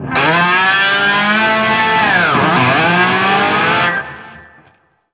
DIVEBOMB 8-R.wav